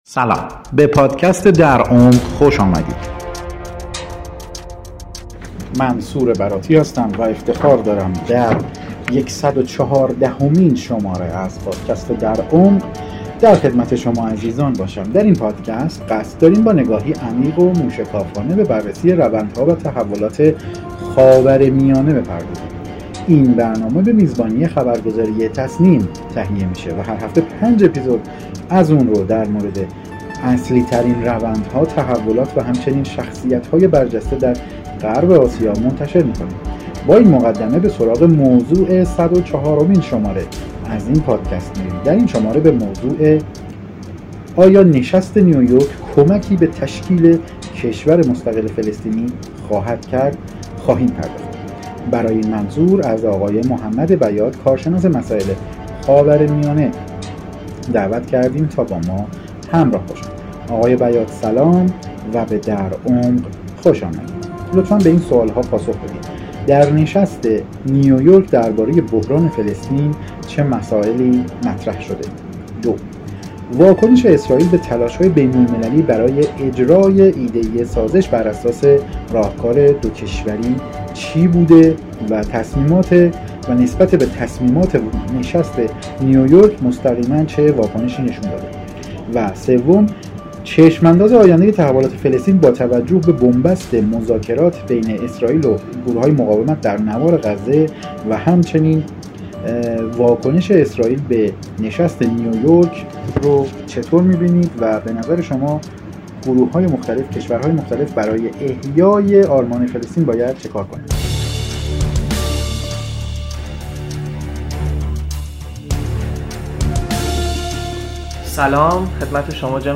کارشناس مسائل خاورمیانه است.